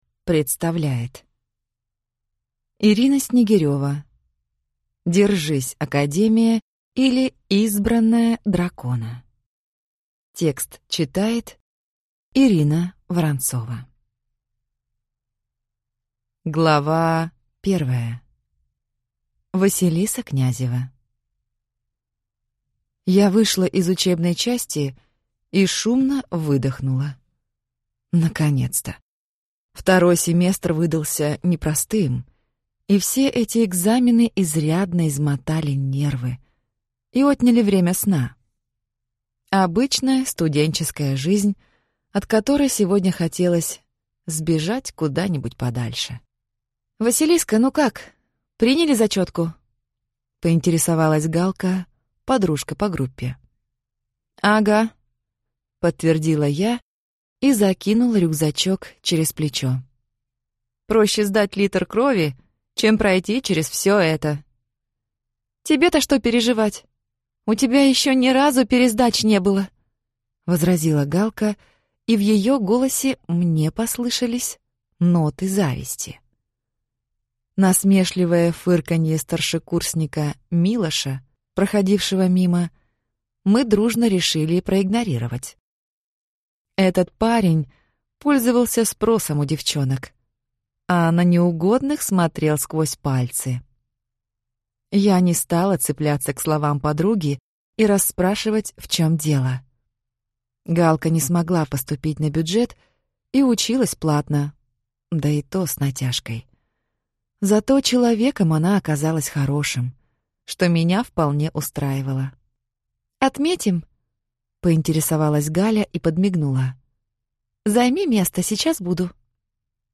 Аудиокнига Держись, Академия! или Избранная дракона | Библиотека аудиокниг